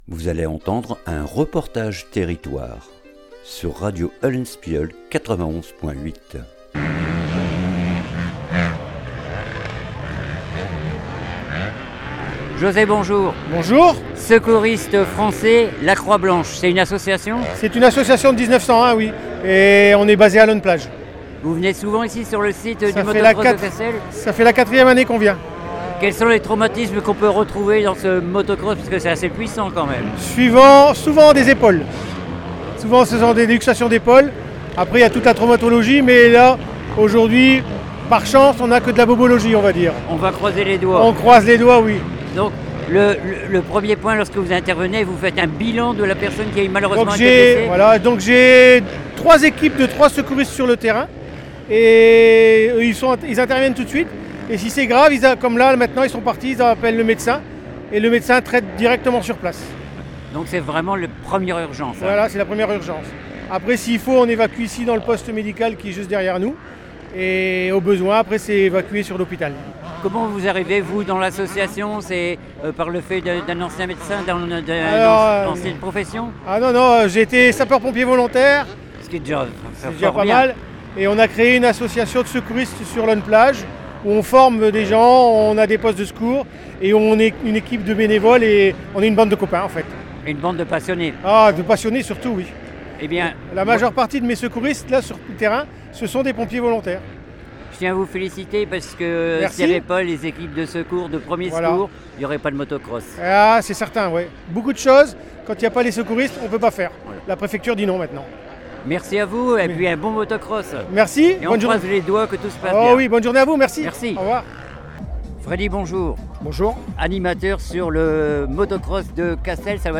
REPORTAGE TERRITOIRE MOTO CROSS 2025 CASSEL